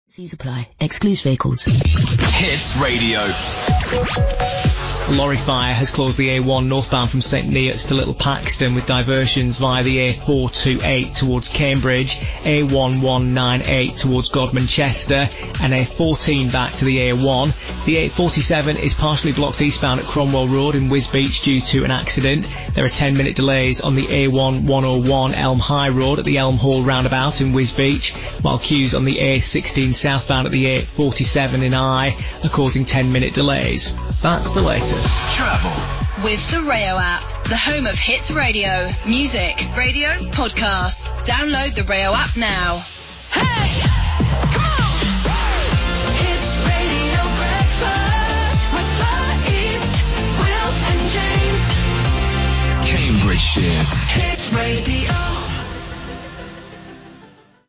Most of the traffic bulletins on Greatest Hits Radio and Hits Radio are now voiced by computers rather than people.
Bulletins are generated through INRIX’s traffic intelligence and automation systems, with delivery informed by the tone and style of our experienced broadcast journalists to ensure continuity and familiarity for listeners.
Here are some examples of the bulletins.